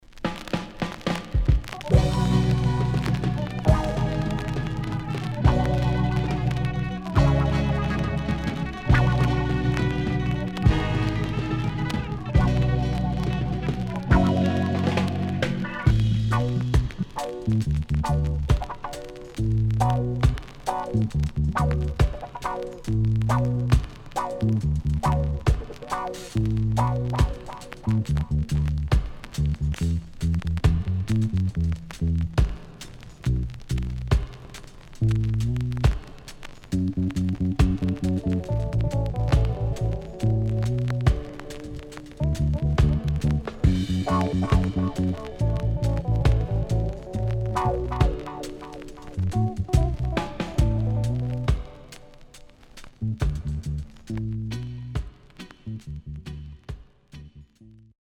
HOME > Back Order [VINTAGE 7inch]  >  KILLER & DEEP
Rare.渋Vocal
SIDE A:所々チリノイズ、プチパチノイズ入ります。